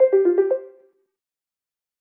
BMW_ringtone.wav